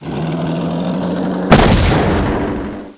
ELEPHANT.WAV